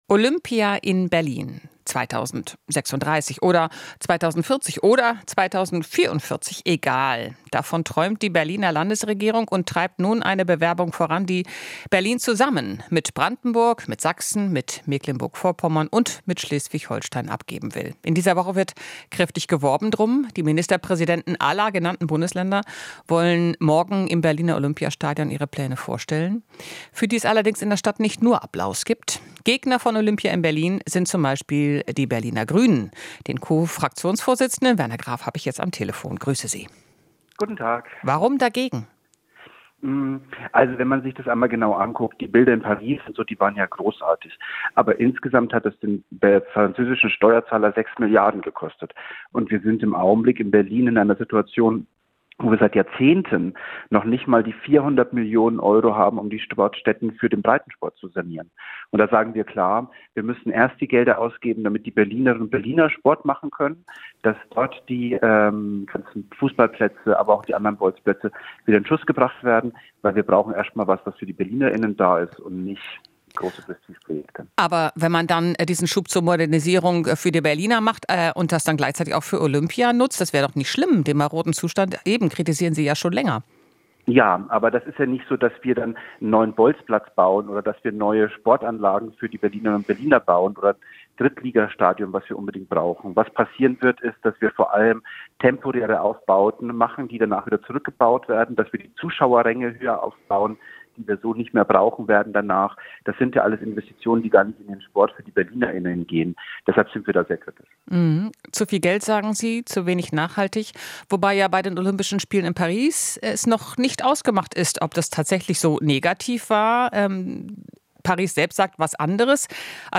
Interview - Graf (Grüne): Bewerbung für Olympia "vorne und hinten nicht seriös"